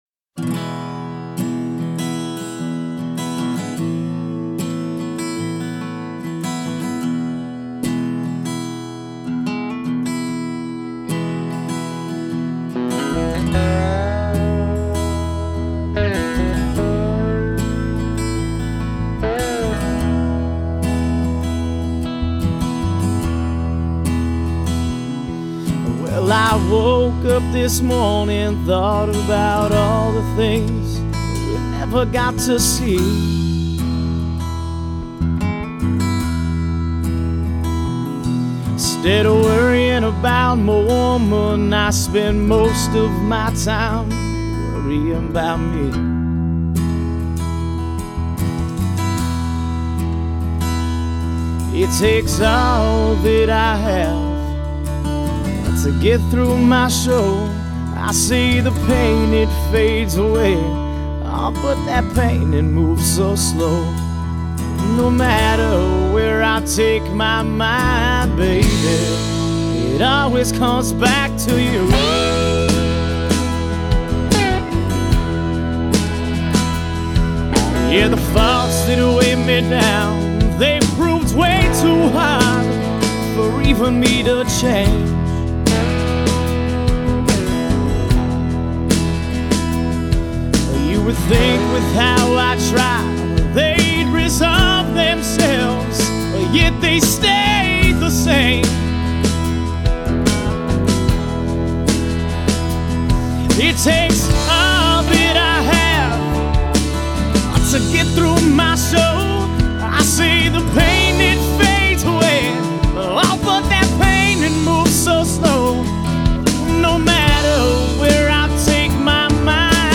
Genre: Blues / Southern Rock
Vocals, Guitar, Harmonica